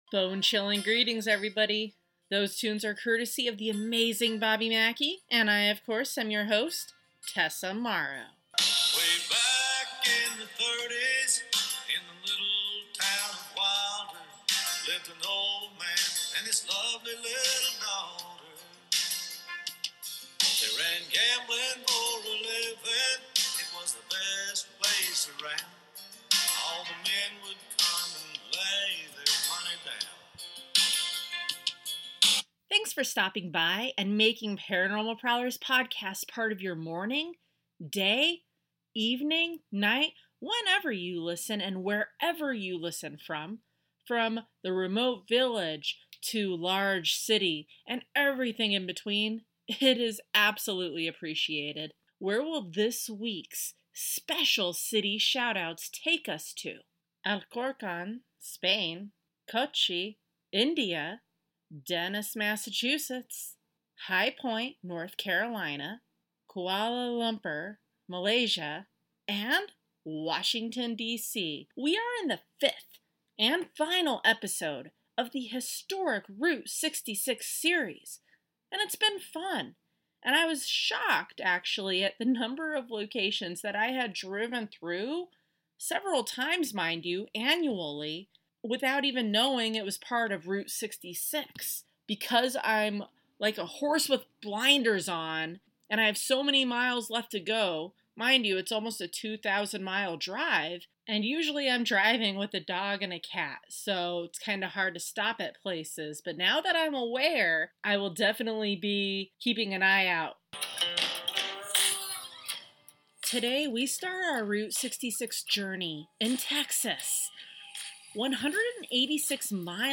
Train passing by Yuma Territorial Prison - May 2024